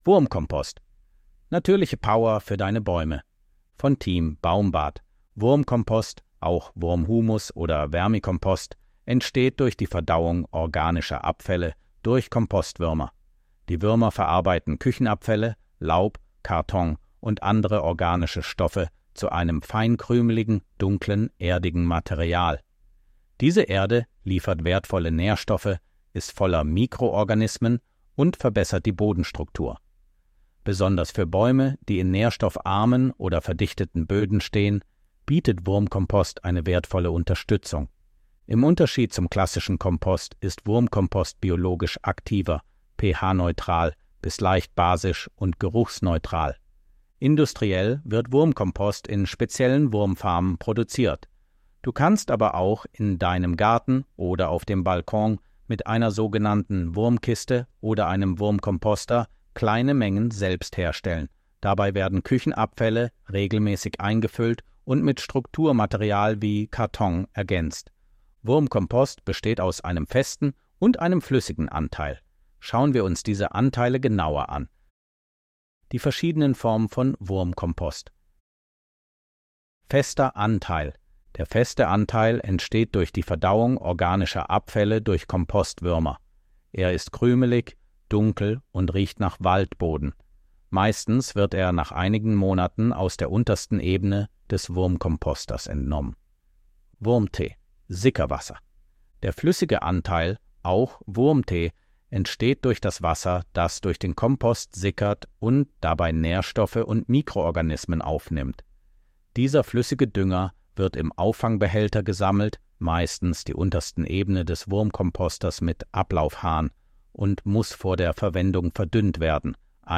von Team baumbad 22.07.2025 Artikel vorlesen Artikel vorlesen Wurmkompost, auch Wurmhumus oder Vermikompost, entsteht durch die Verdauung organischer Abfälle durch Kompostwürmer.